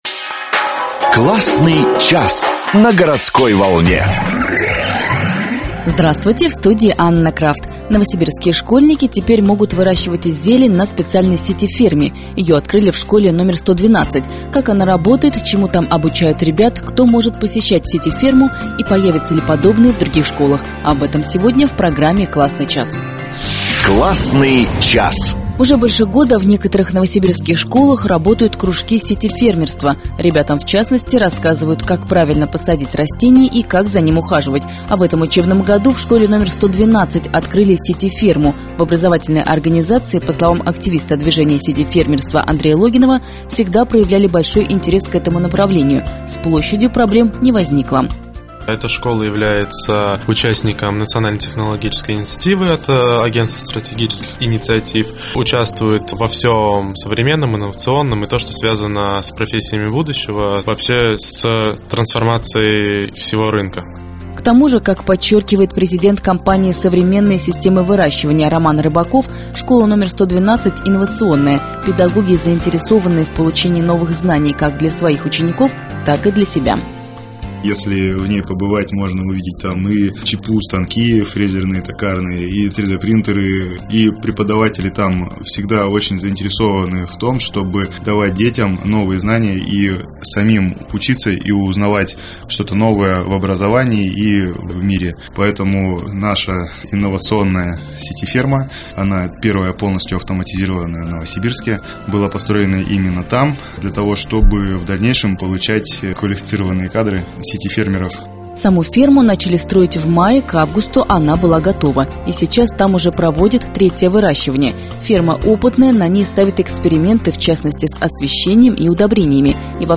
19 ноября 2018 года вышла радио-передача Классный час.
Интервью для программы Классный час у активистов движения сити-фермерства на радио Городская Волна